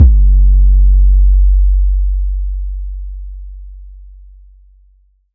MB Kick (24).wav